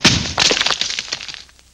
На этой странице собраны звуки штукатурки: от мягкого шуршания до резких скребущих движений.
Звук броска в стену и обвалившейся штукатурки